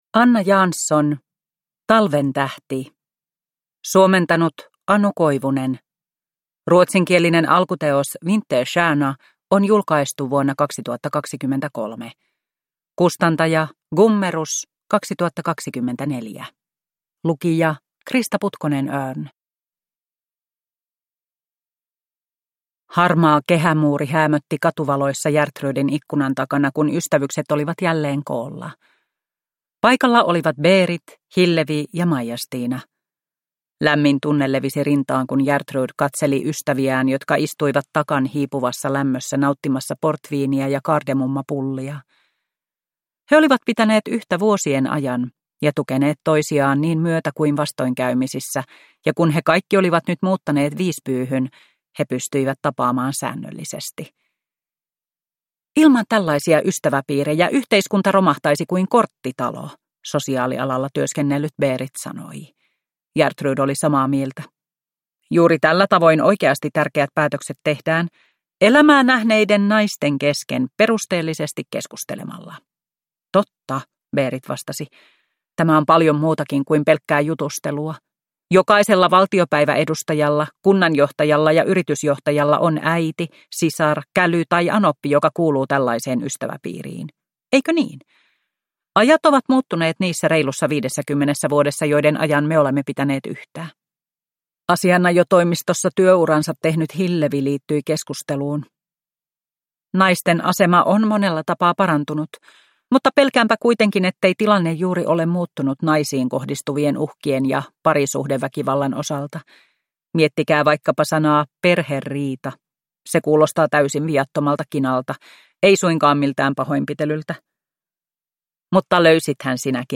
Talventähti – Ljudbok